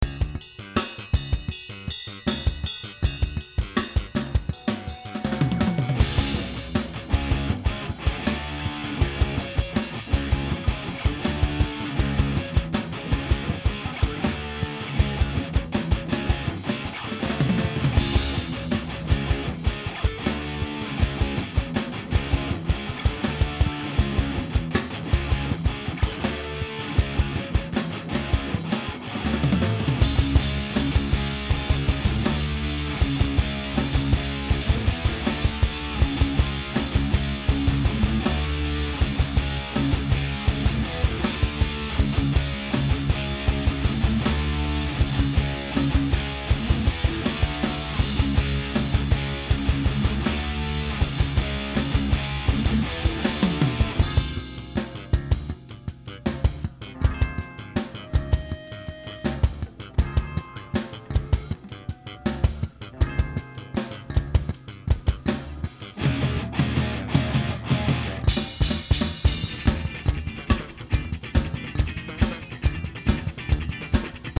Un petit jeu, je suis au studio pour quelques heures :
Une petite mise à plat, sans EQ, ni compression (attention aux gamelles pour ceux qui n'ont pas ce qui va bien), quels sont les amplis en disto et en clair ???????
Mon Dual en KT77 dans un baffle marchemal - G12M ROLA, le clair aussi.